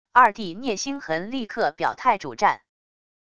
二弟聂星痕立刻表态主战wav音频生成系统WAV Audio Player